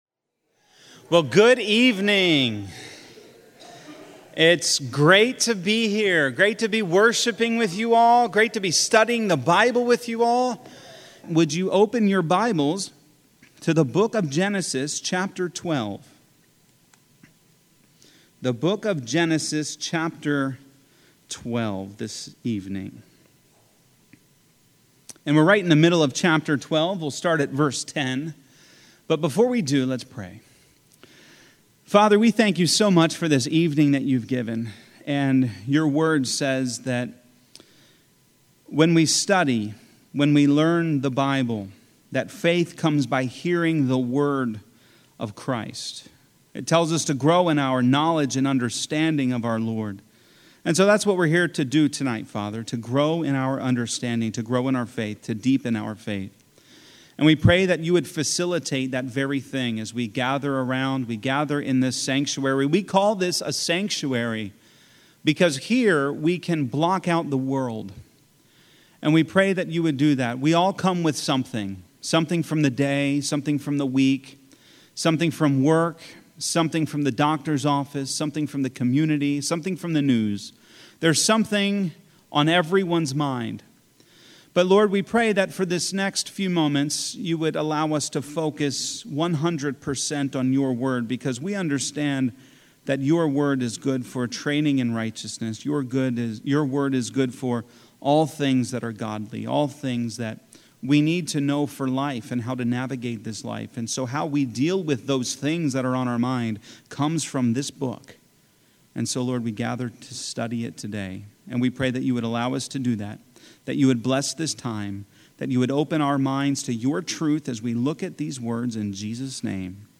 At Shepherd, we believe in Bible teaching.